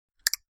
Clic bouton.mp3